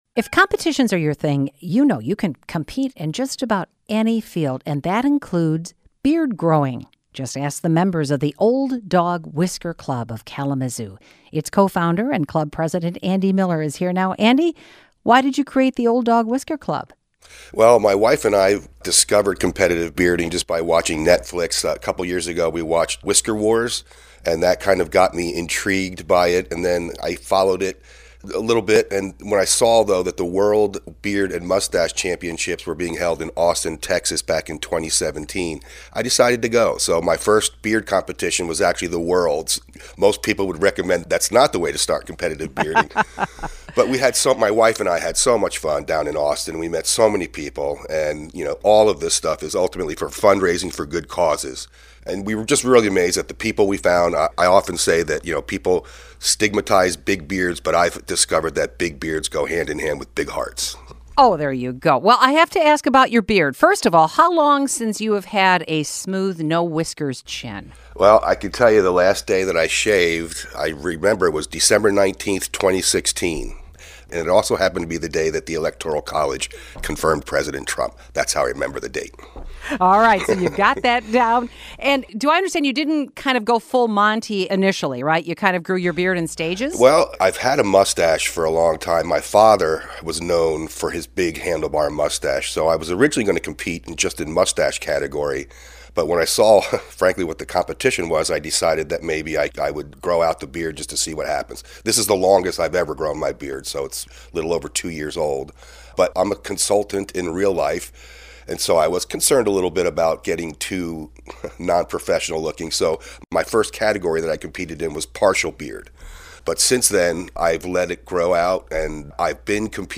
Stateside's conversation